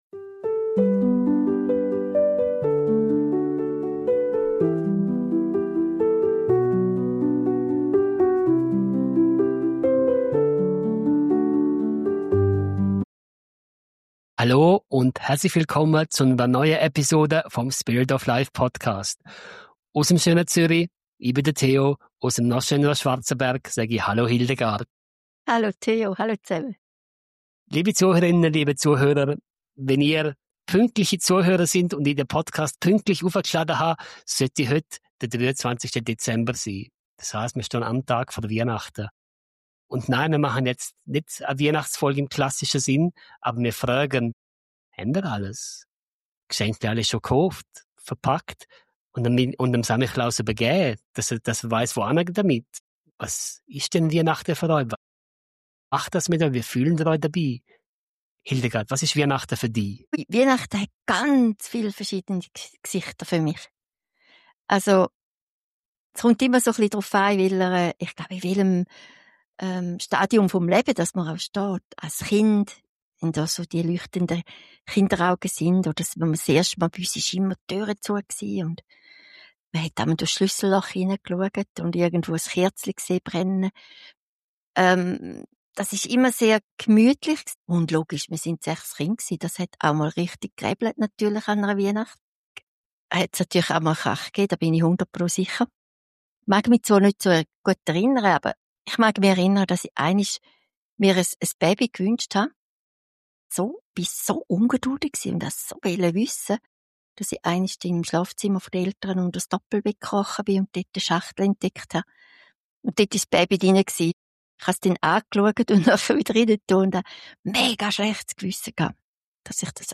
Eine ruhige, herzliche Episode, die einlädt, Weihnachten als das zu erleben, was es im Kern sein kann: ein Moment von Menschlichkeit, Verbundenheit und innerem Licht.